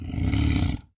sounds / monsters / cat / c2_hit_2.ogg
c2_hit_2.ogg